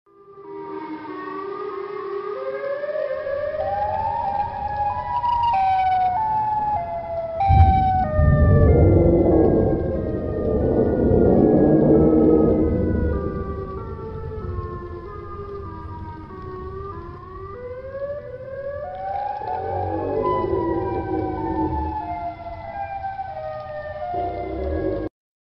На этой странице собраны звуки сиреноголового — жуткие аудиозаписи, создающие атмосферу страха и неизвестности.
Сиреноголовый звук (жуткий) Чикагская сирена